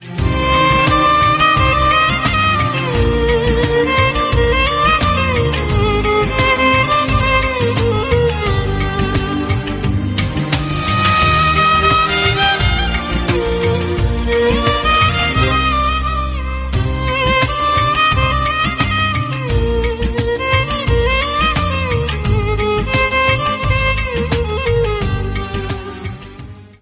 Hindi Ringtones